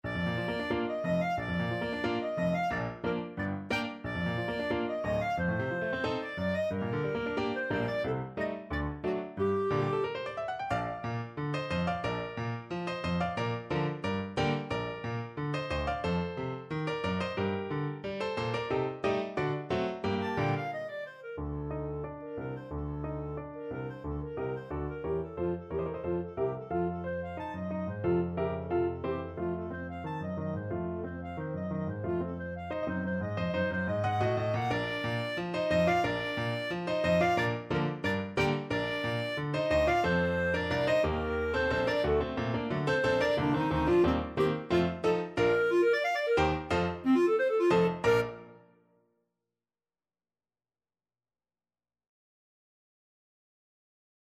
Clarinet
~ = 100 Allegro giocoso, ma non troppo vivace =90 (View more music marked Allegro giocoso)
2/4 (View more 2/4 Music)
C5-A6
Classical (View more Classical Clarinet Music)
brahms_violin_concerto_3rd_CL.mp3